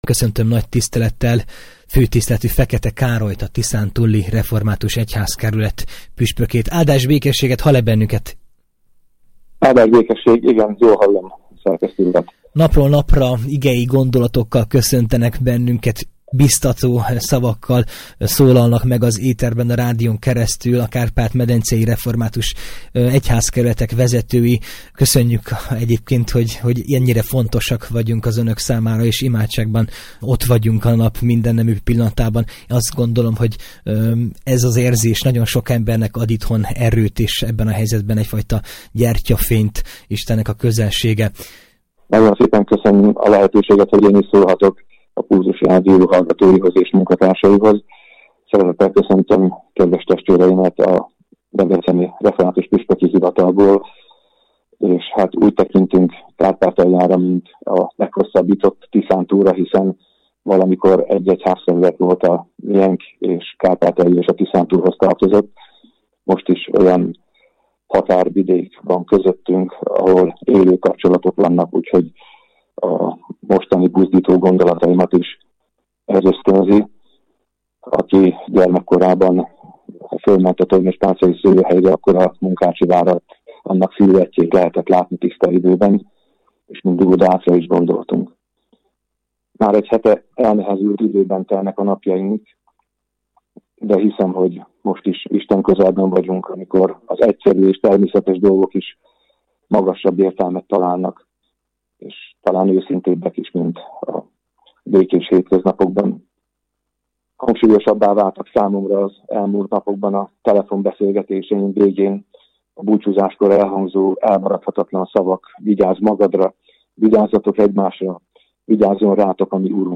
Bátorító Üzenetek - Fekete Károly, püspök